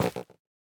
Minecraft Version Minecraft Version 1.21.5 Latest Release | Latest Snapshot 1.21.5 / assets / minecraft / sounds / block / fungus / break4.ogg Compare With Compare With Latest Release | Latest Snapshot
break4.ogg